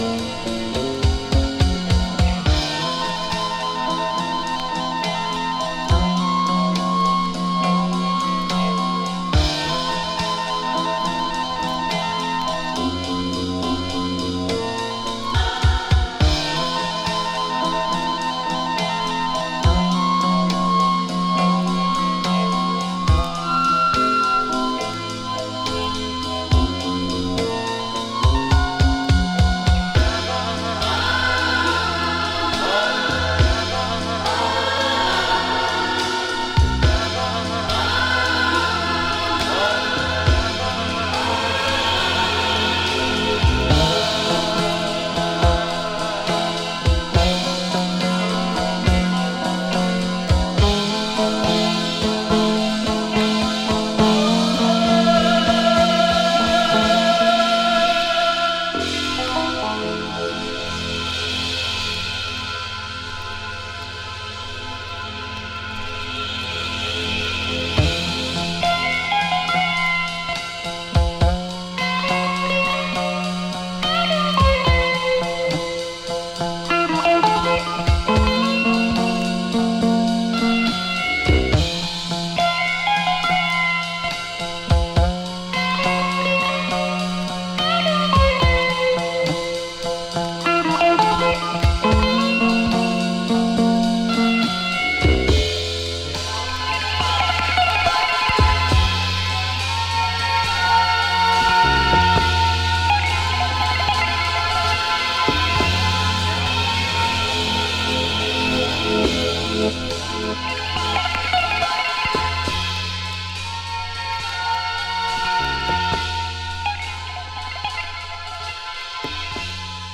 Soul Funk